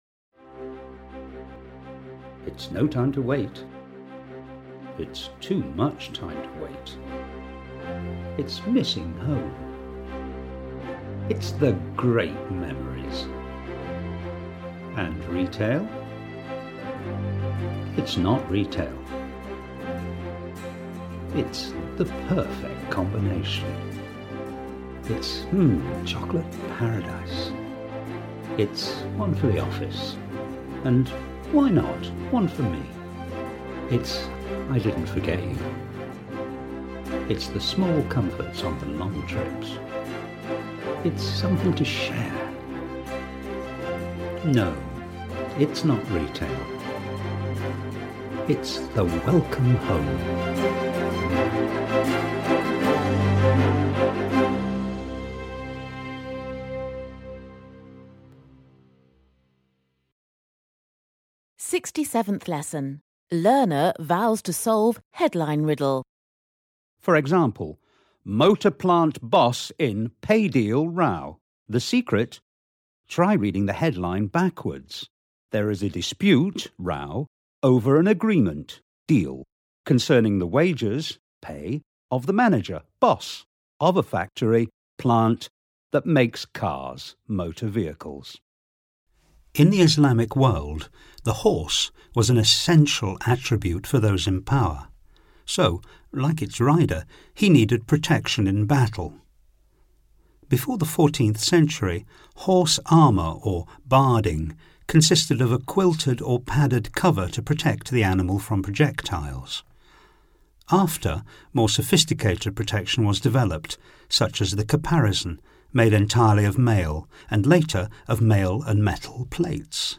Voix off
10 - 90 ans - Baryton